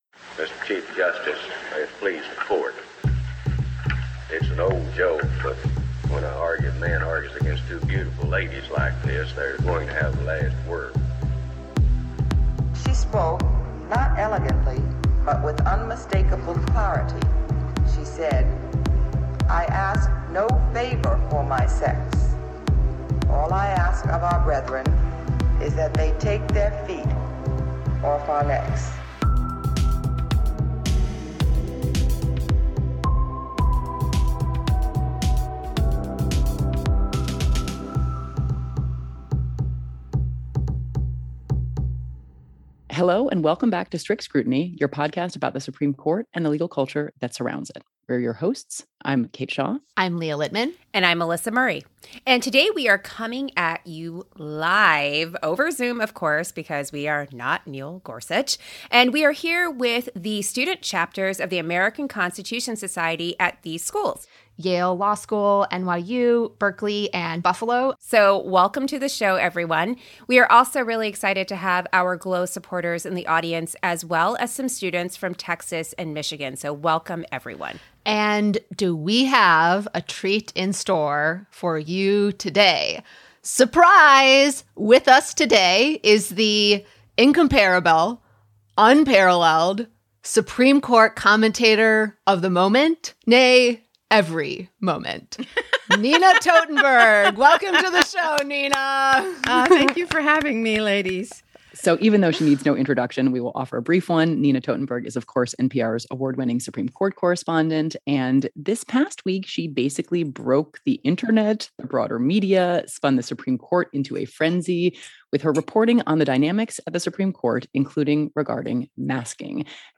Nina Totenberg joins the podcast to discuss her story that broke the Internet and sent the Court into a statement frenzy.